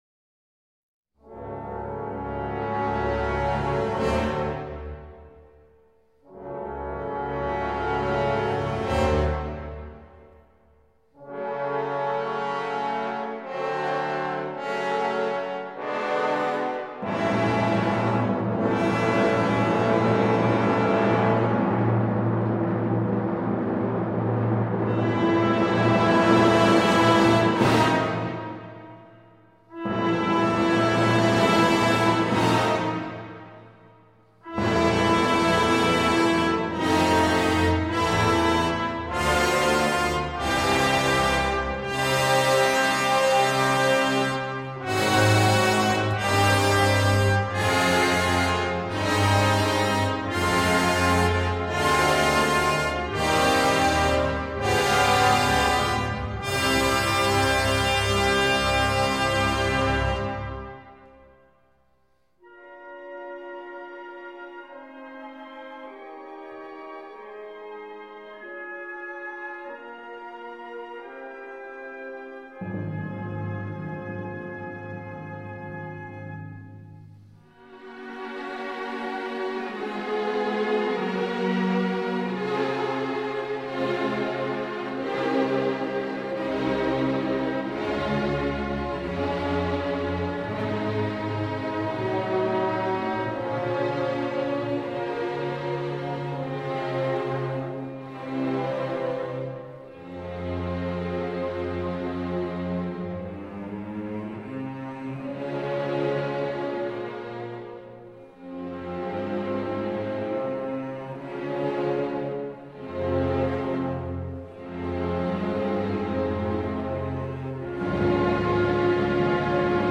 Romantic nationalism.